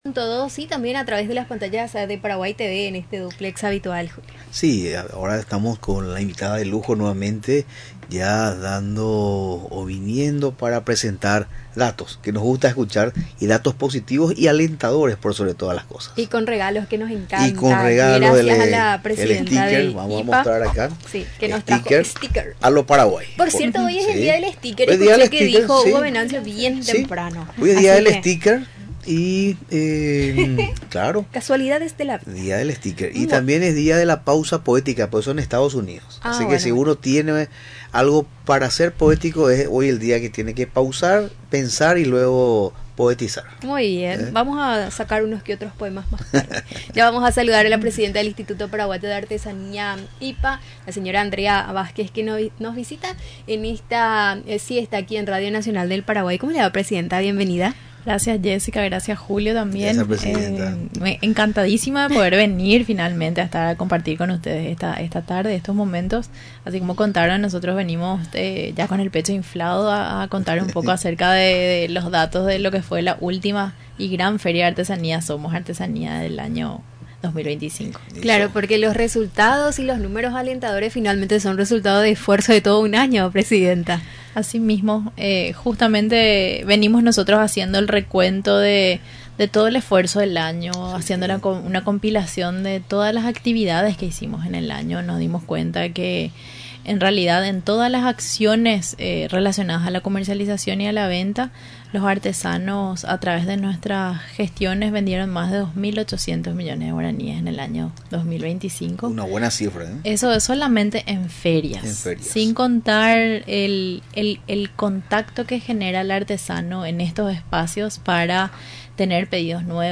Durante su visita a los estudios de Radio Nacional del Paraguay, resaltó que las ventas en las citadas jornadas, se produjo de la siguiente manera: